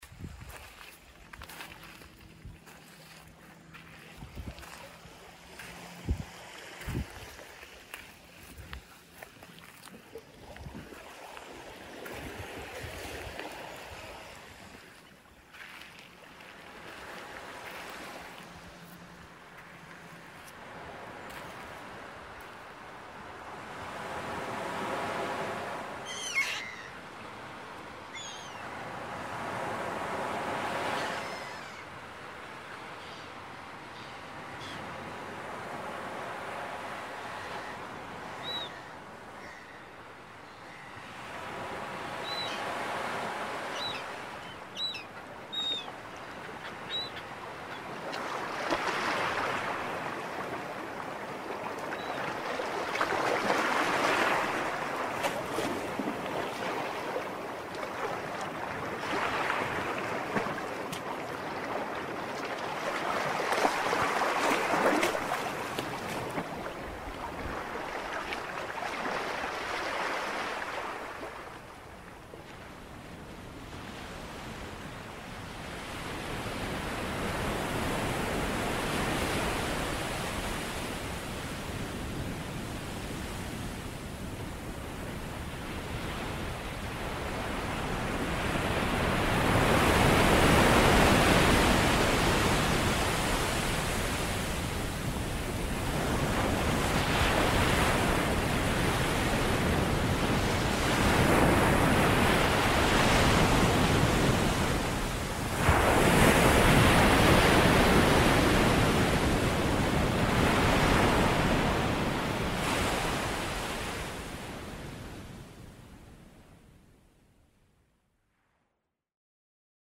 Drawn from real coastal recordings, the artwork captures the gentle rhythm of waves washing over sand, the scattered splash of water hitting rocks, the soft crunch of barefoot steps, and the distant calls of seagulls overhead.
praia_mixagem.mp3